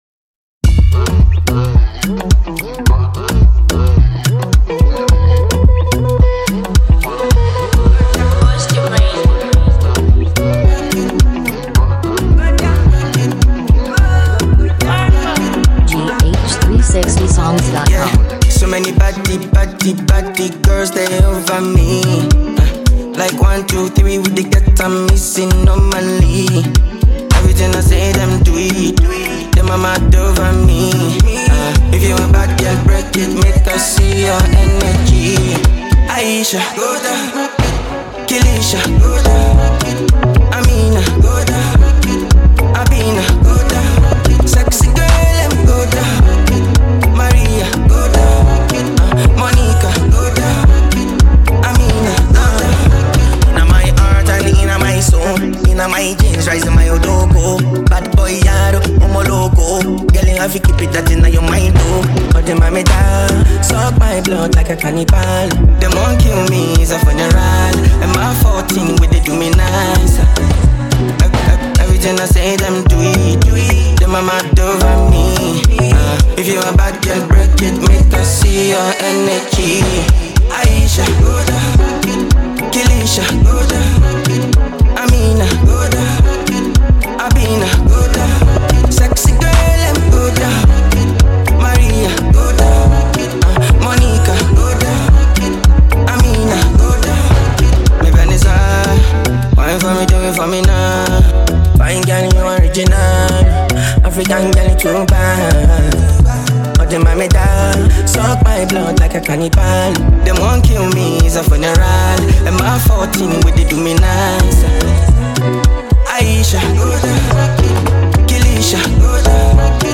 Afrobeat
Afro-pop and dancehall fusion
soulful voice